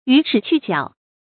予齒去角 注音： ㄧㄩˇ ㄔㄧˇ ㄑㄩˋ ㄐㄧㄠˇ 讀音讀法： 意思解釋： 謂天生動物賦予齒就不賦予角。